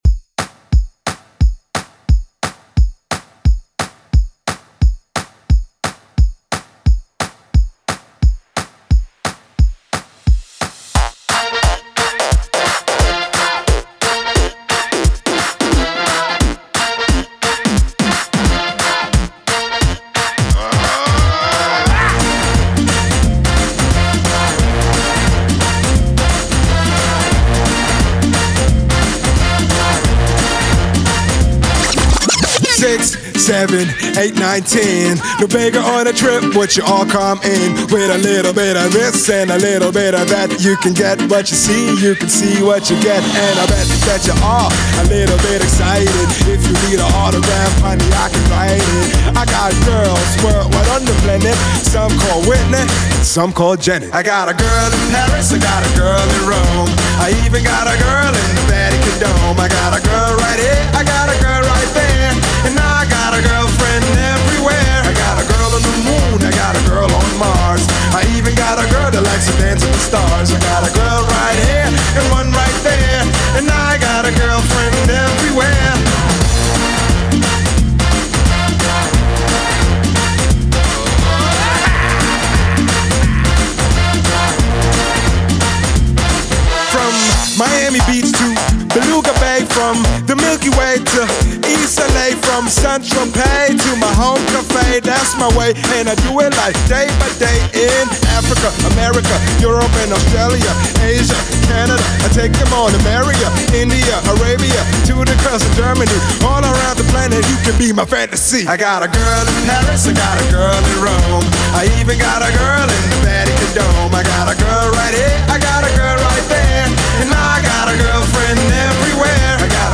Genre: 80's
BPM: 128